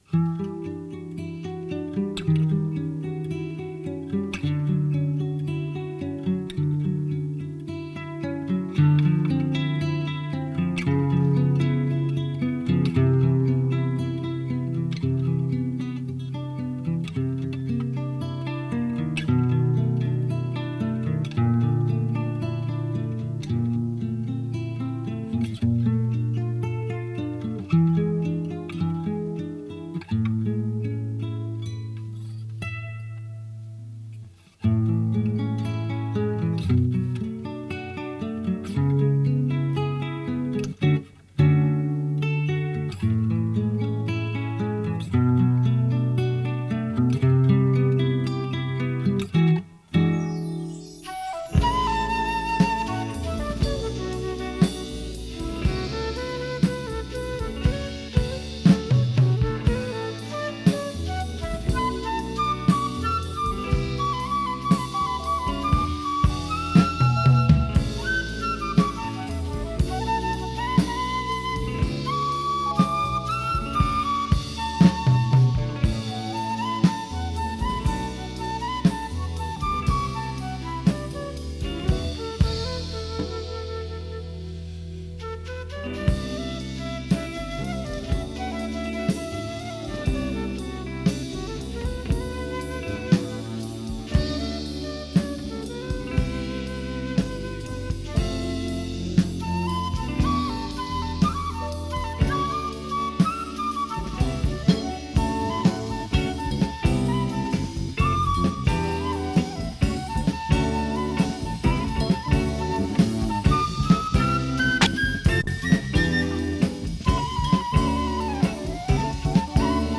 Her er nogle eksempler på nogle 4 spors optagelser, mikset ned på Chrom kasette bånd og kopieret til et andet Chrom kasette bånd og gemt væk i en kælder i 25 år.